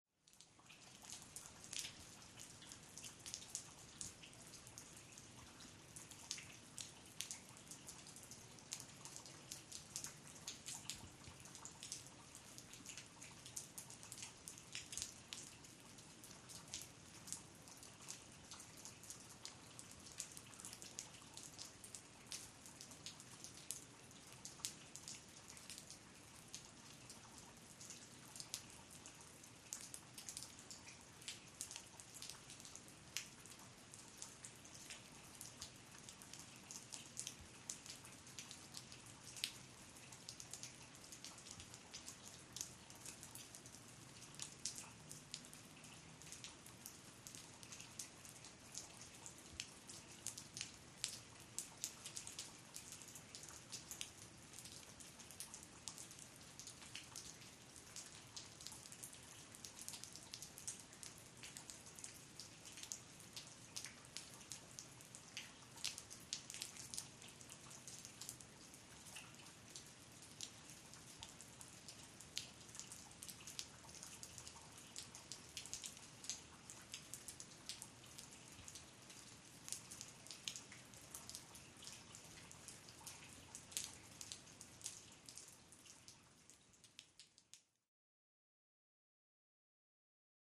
Water, Rain | Sneak On The Lot
Light Rain Falling Off The Roof Onto Pavement